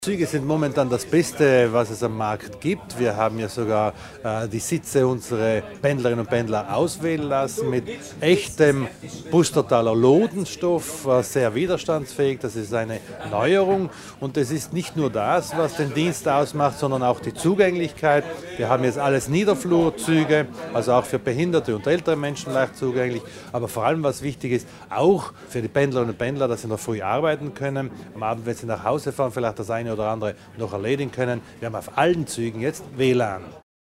Landesrat Widmann erklärt die Eigenschaften der neuen Flirt-Züge